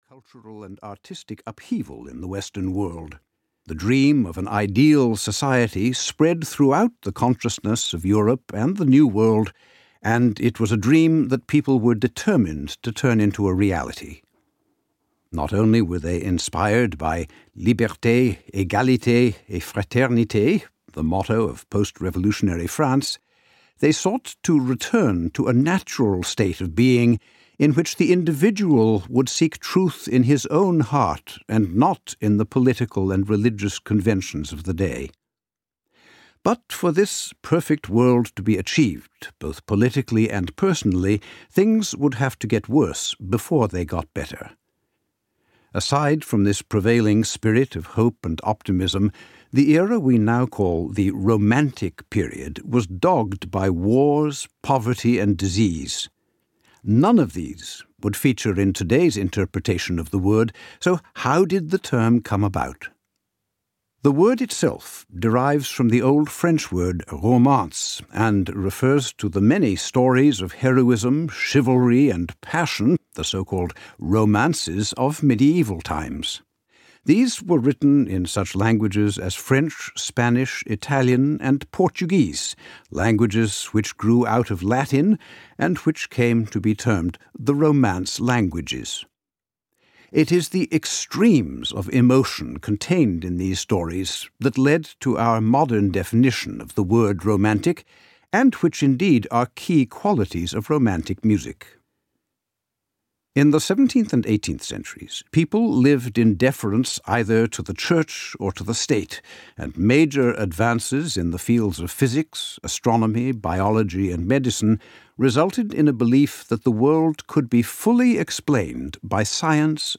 Discover Music of the Romantic Era (EN) audiokniha
Ukázka z knihy
Discover Music of the Romantic Era charts the course of music through these turbulent but exhilarating times with more than 20 music excerpts. Lighthearted but authoritative, it tells the fascinating, often scandalous life stories of the nineteenth century’s greatest composers and describes how they created musical history.